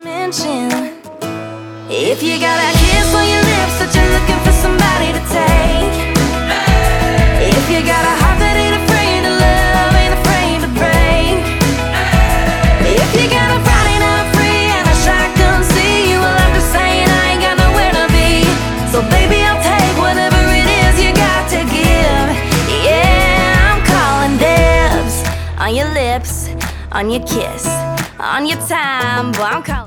• Pop
American country pop singer